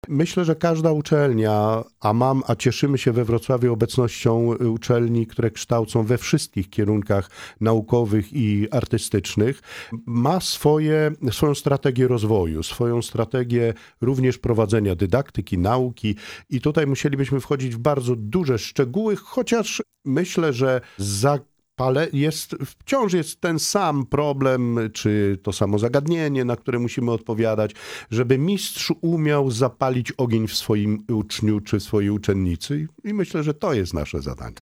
Gościem audycji „Poranny Gość” był ks. prof. dr hab.